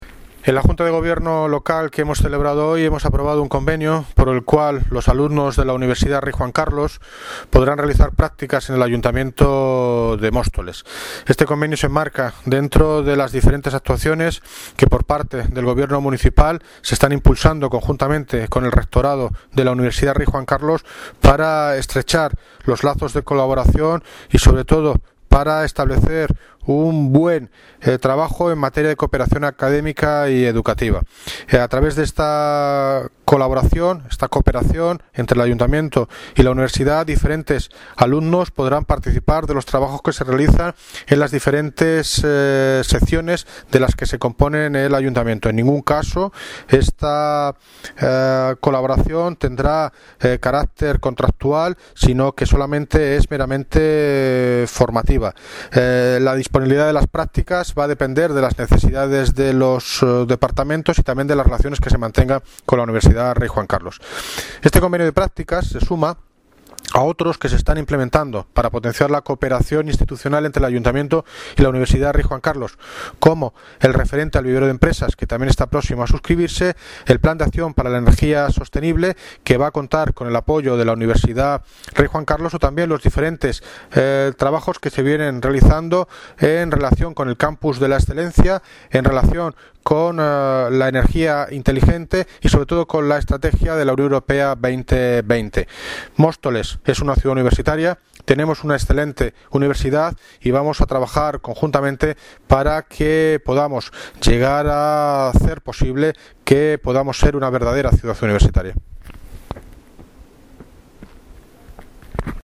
Audio - David Lucas (Alcalde de Móstoles) sobre convenio prácticas con la Universidad Rey Juan Carlos